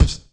cch_01_kick_one_shot_low_lofi_burst.wav